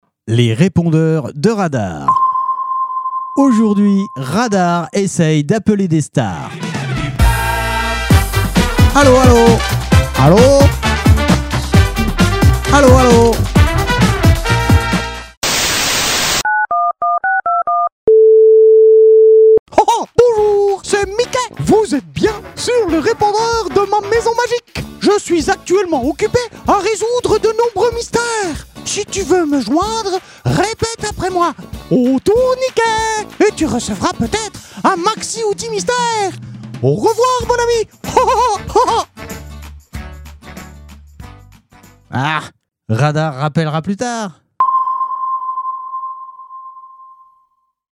Les répondeurs de Radar parodies répondeurs stars radar